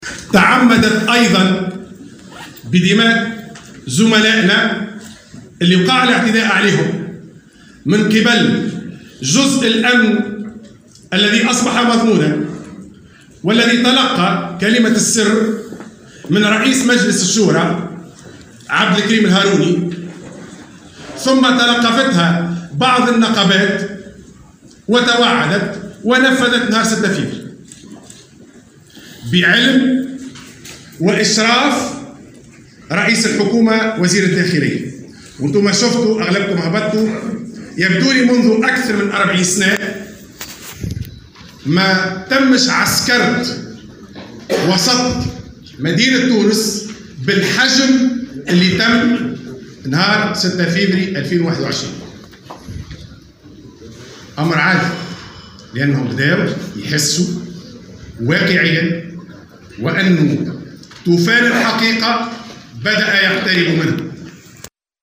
ندوة صحفية